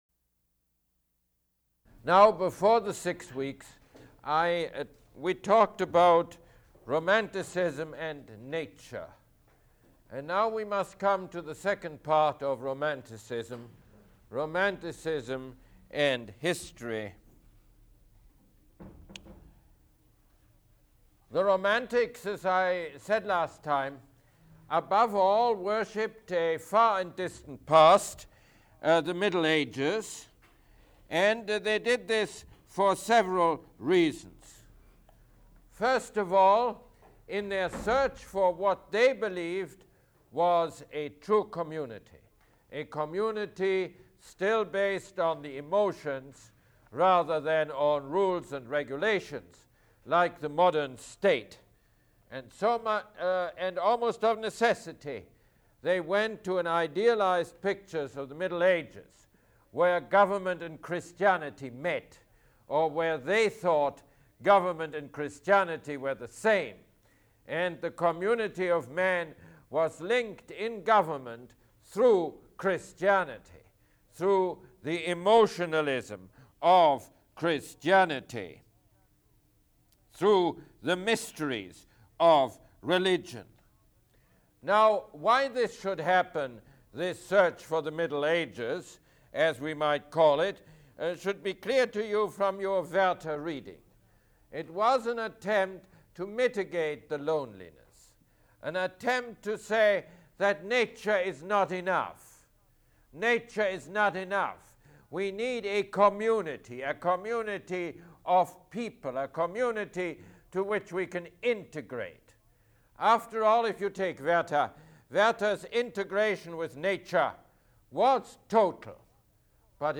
Mosse Lecture #15